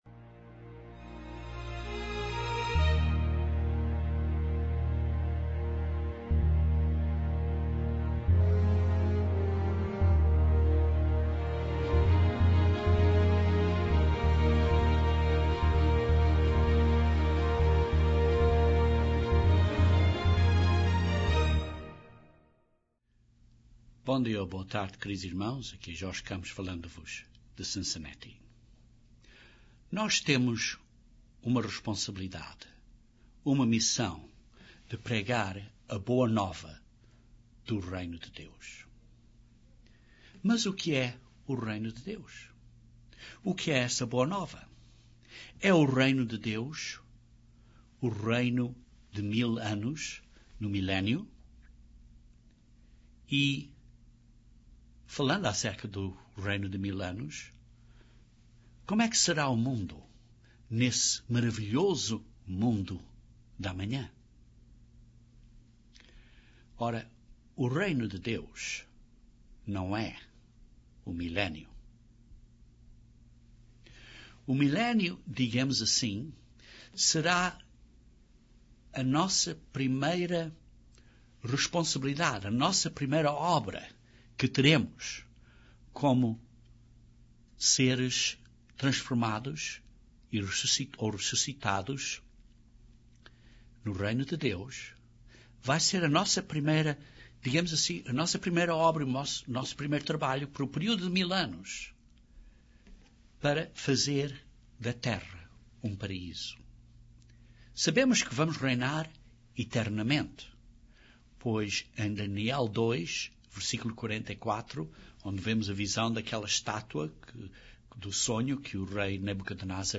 Uma das primeiras grandes responsibilidades do Reino de Deus será transformar este mundo de tristezas, crime, e sofrimento, para um maravilhoso mundo. Usando as escrituras acerca do reino milenial, este sermão pinta uma gravura do maravilhoso mundo de amanhã.